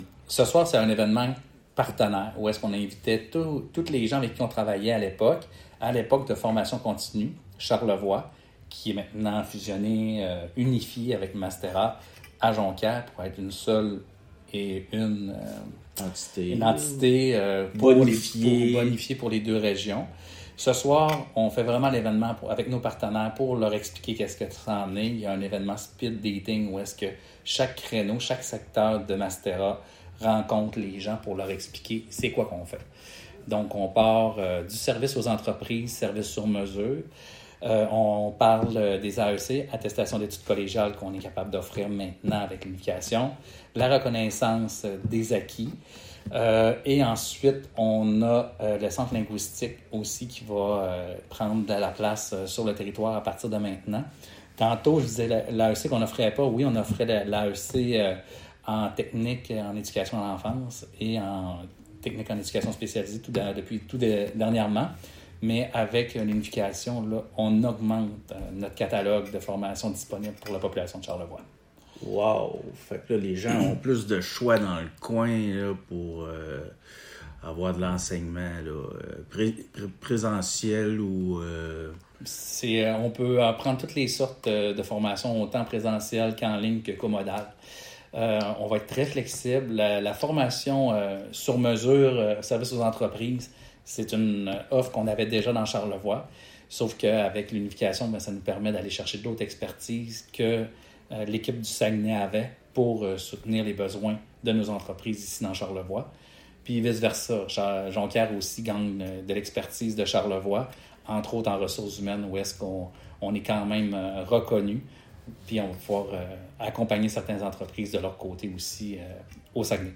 Cet événement marquant a été célébré hier soir, mercredi, lors d’un rassemblement de partenaires tenu à la Maison Mère de Baie-Saint-Paul.